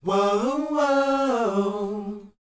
WHOA G#A.wav